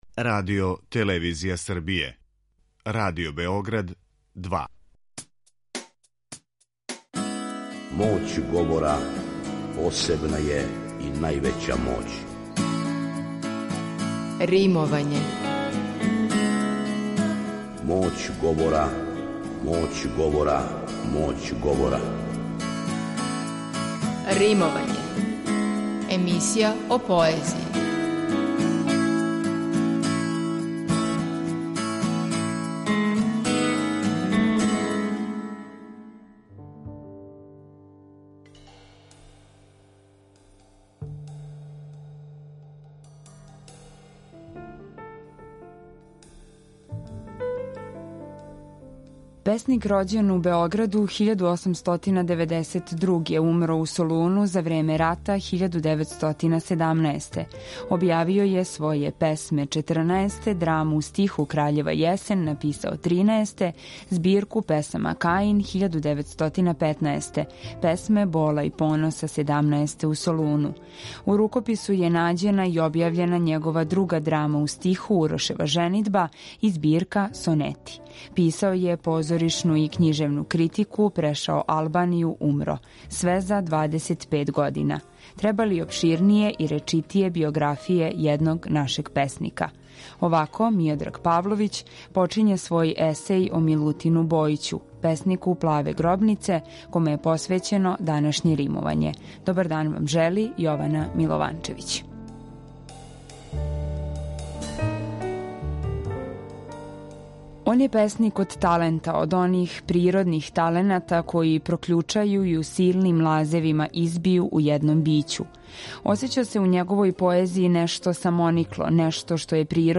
У новој емисији посвећеној поезији, слушаоци ће моћи да чују избор стихова из Звучног архива Радио Београда које говоре најчувенији домаћи и инострани песници, драмски уобличене поетске емисије из некадашње серије „Вртови поезије", као и савремено стваралаштво младих и песника средње генерације.
У данашњој емисији говоримо о животу, али пре свега о стваралаштву песника "Плаве гробнице". Осим те, песме коју говори Милош Жутић, чућемо и друге Бојићеве стихове у интерпретацијама Петра Банићевића и Драгана Петровића.